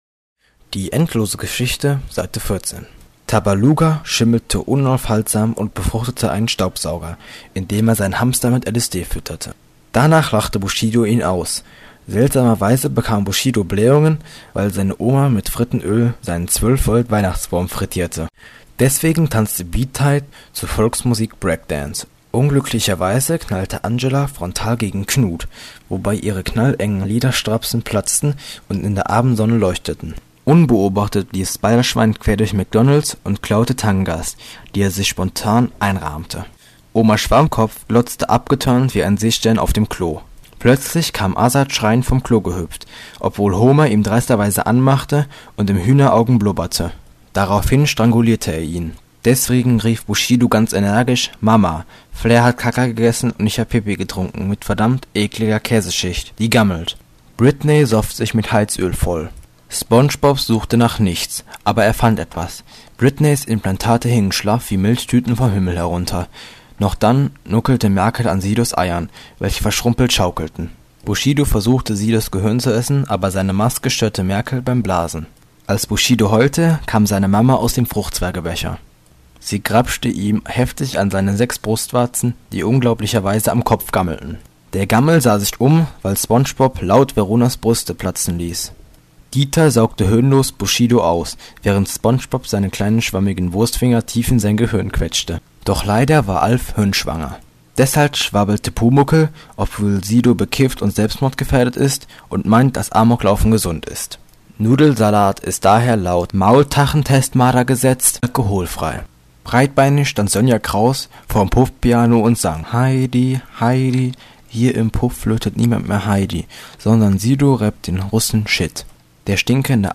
Das Hörbuch zur endlosen Story, Seite 14